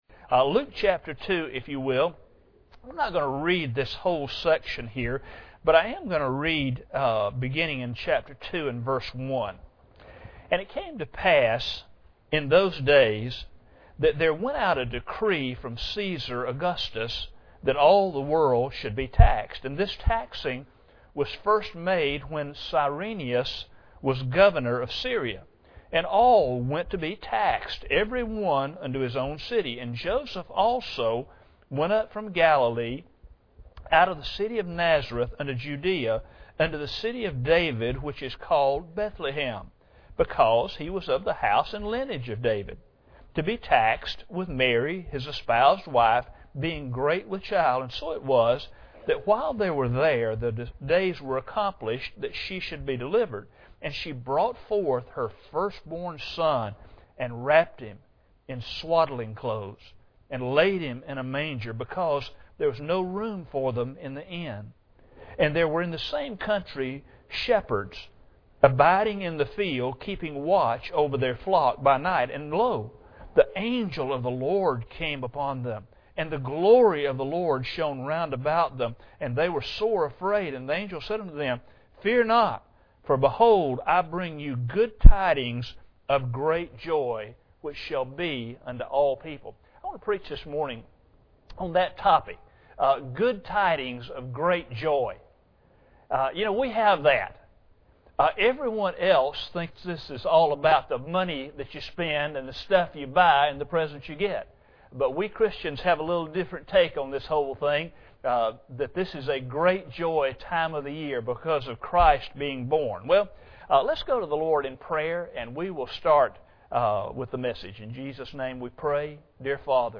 Luke 2:1-10 Service Type: Sunday Morning Bible Text